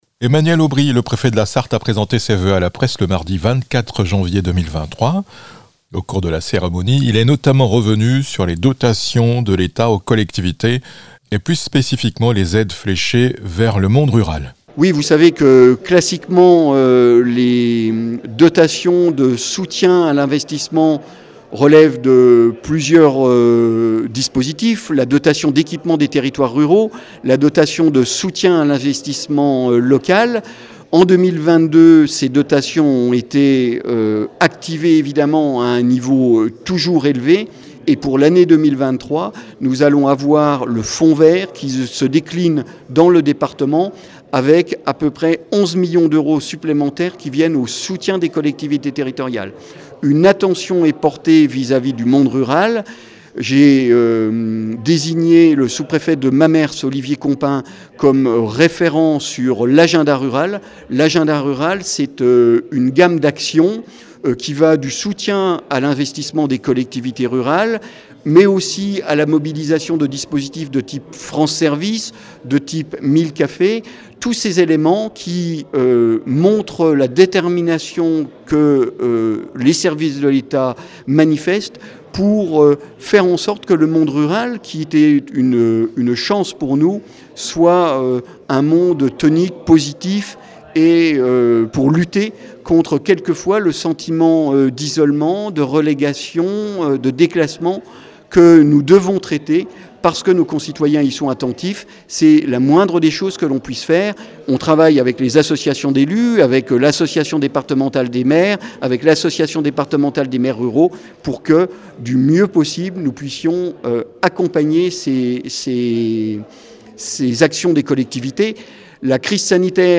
Emmanuel Aubry, préfet de la Sarthe, a adressé ses voeux à la presse le 24 janvier 2023. Lors de la cérémonie, le représentant de l'Etat est revenu sur quelques grands dossiers qui ont marqué 2022. Puis il a annoncé les couleurs pour 2023 : la sécurité avec le plan objectif zéro délinquance à l'approche des grands évènements sportifs, la poursuite de la lutte contre les incendies de forêt, le "fonds vert" fonds d'accélération de la transition écologique dans les territoires.